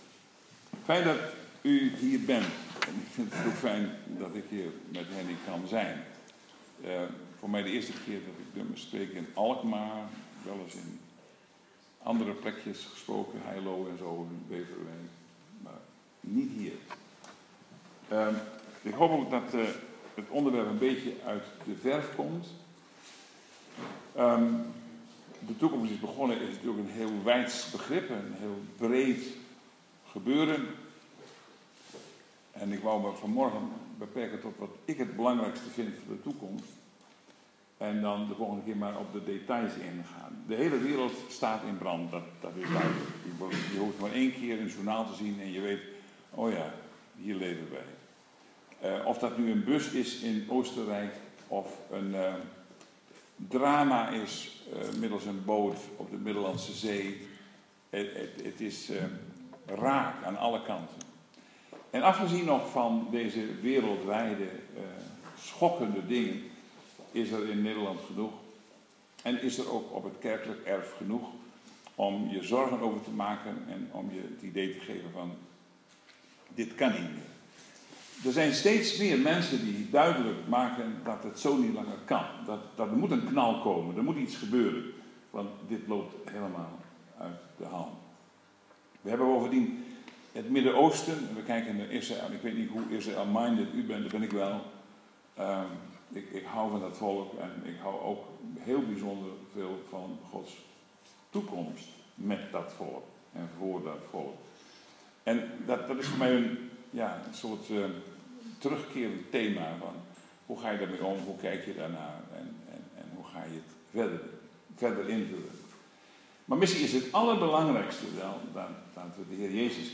Preek zondag 30 augustus 2015 – Vergadering Alkmaar